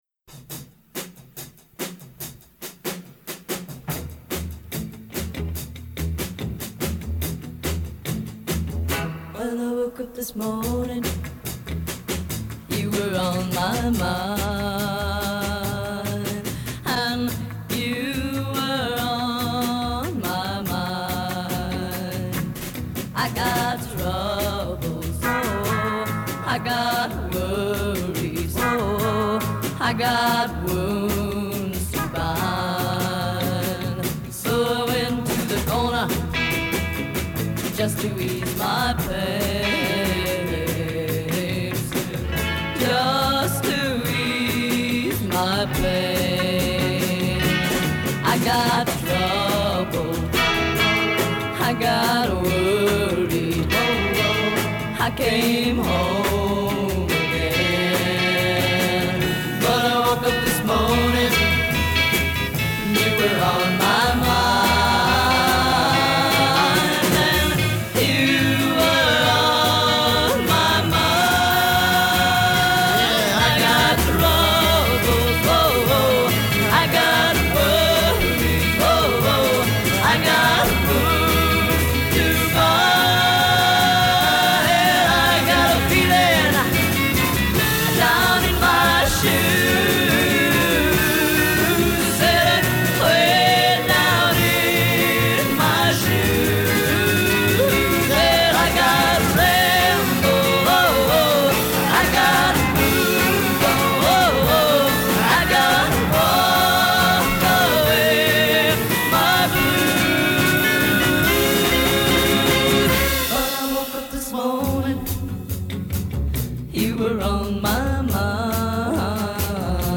took it up-tempo